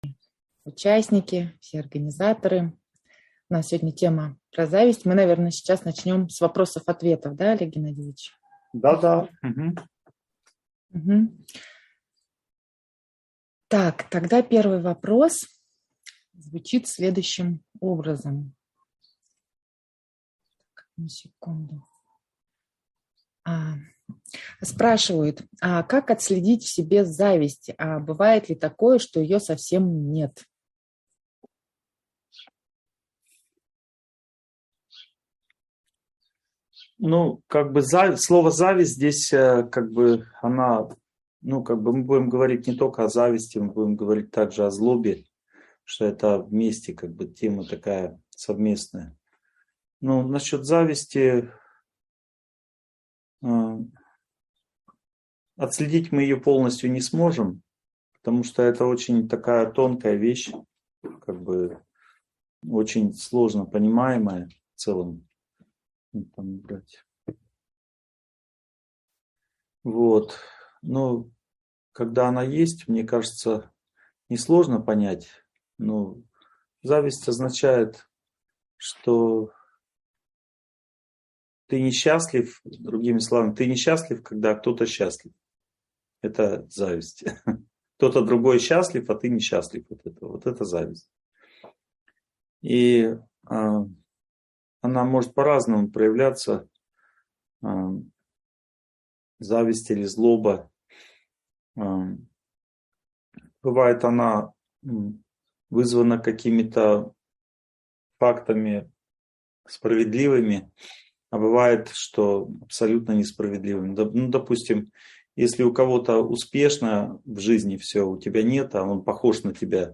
Зависть (онлайн-семинар, 2022)